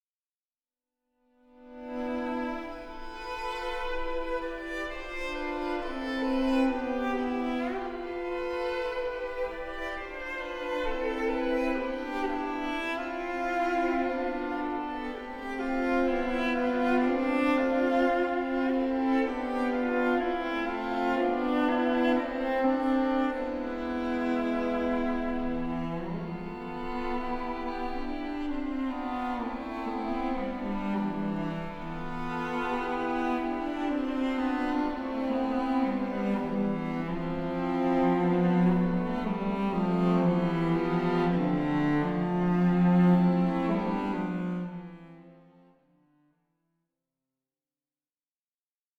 Largo (1.29 EUR)